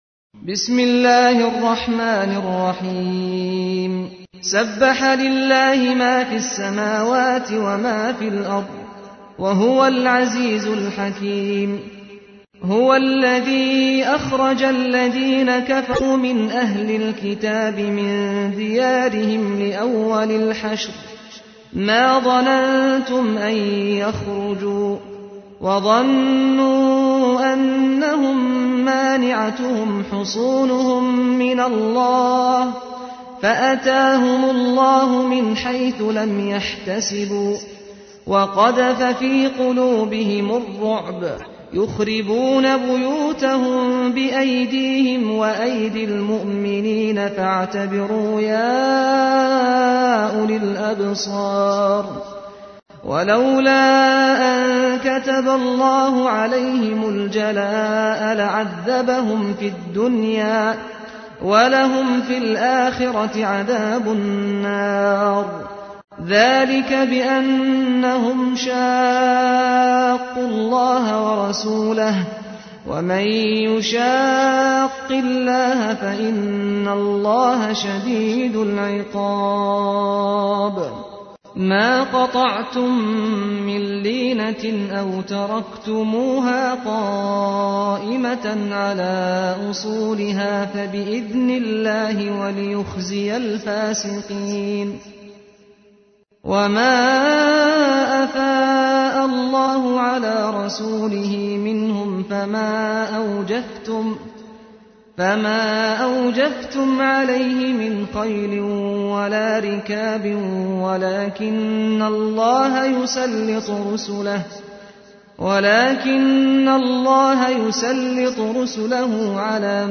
تحميل : 59. سورة الحشر / القارئ سعد الغامدي / القرآن الكريم / موقع يا حسين